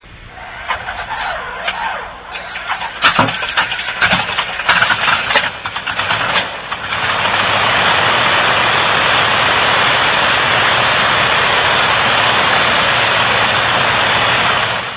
N3485V Jacobs Radial Engine Starting